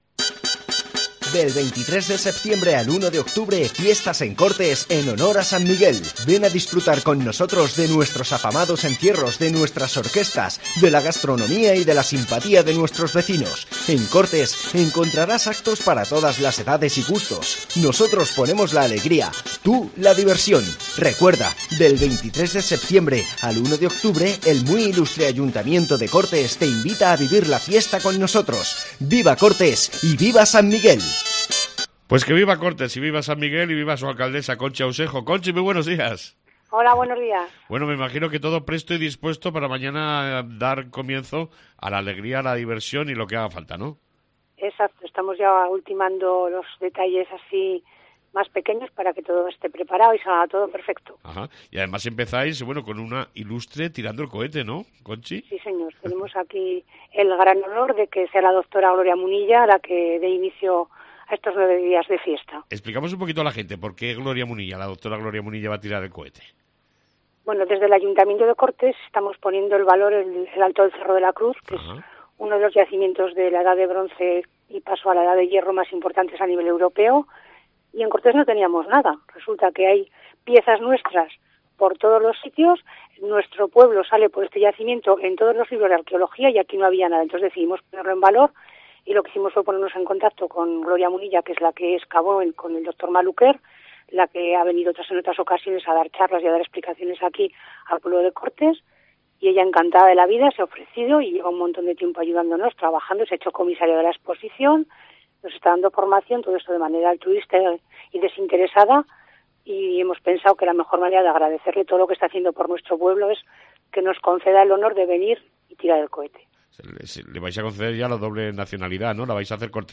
La Alcaldesa de Cortes, conchi Ausejo, nos presenta las fiestas 2017 en honor a San Miguel